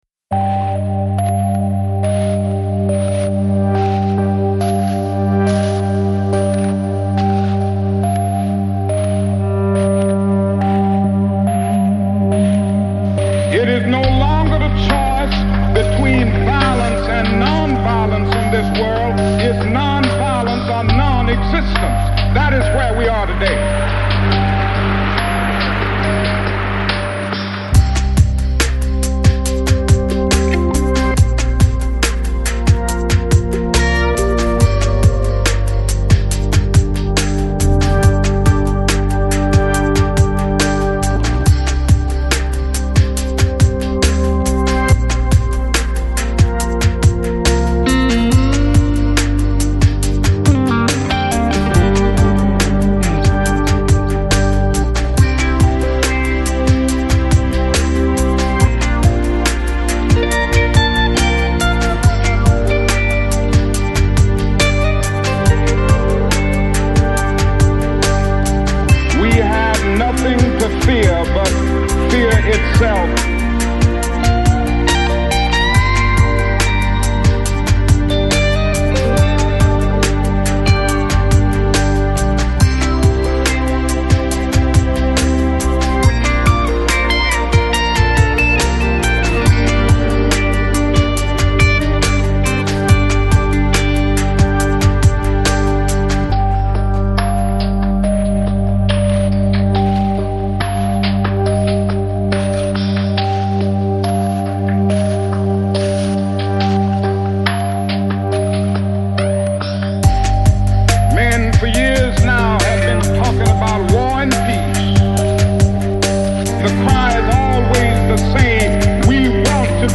Electronic, Lounge, Downtempo, Chill Out